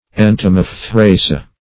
entomophthoraceae.mp3